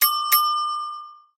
Media:mrp_start_vo_05.ogg Bell chimes
P先生的铃声